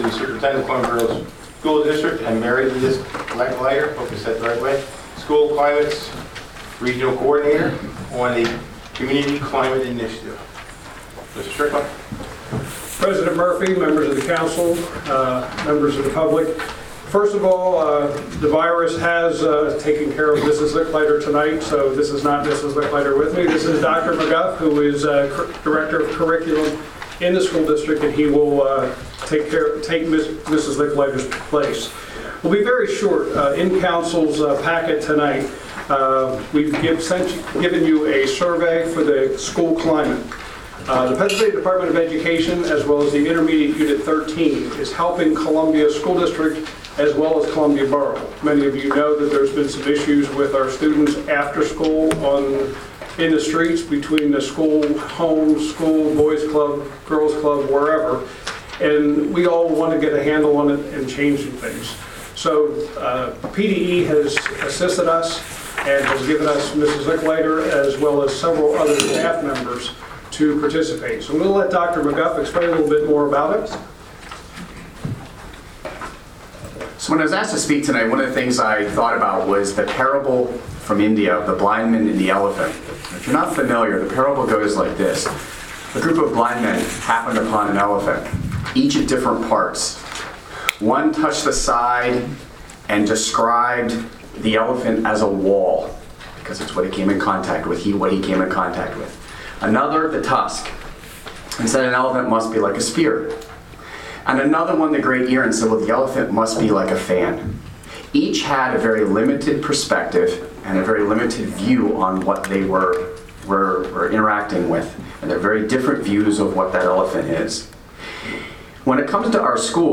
Near the beginning of last Monday’s borough council, Council President Kelly Murphy introduced agenda Item I, 5.
Here’s what the superintendent said: